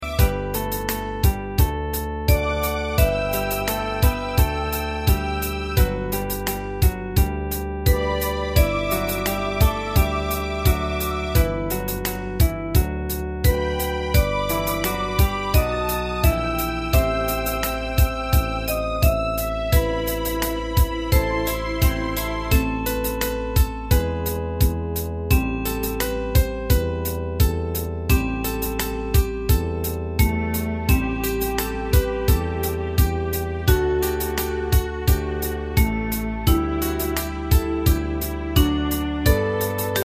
大正琴の「楽譜、練習用の音」データのセットをダウンロードで『すぐに』お届け！
Ensemble musical score and practice for data.